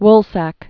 (wlsăk)